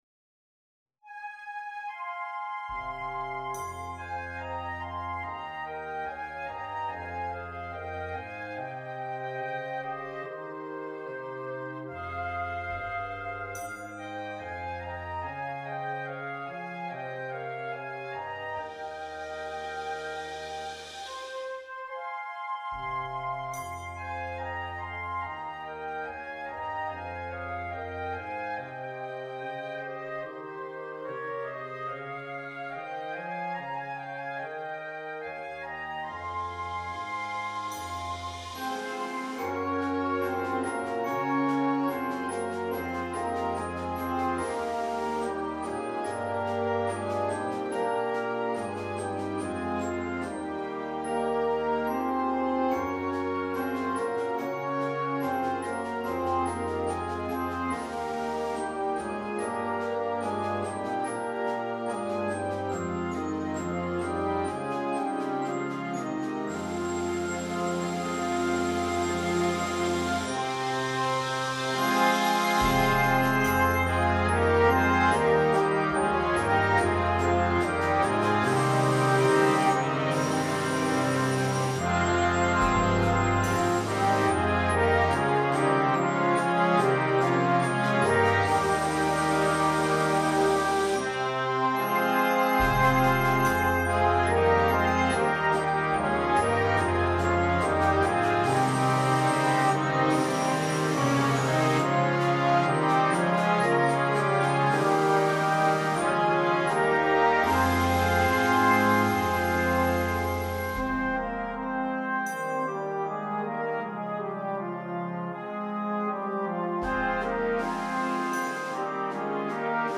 an original composition for concert band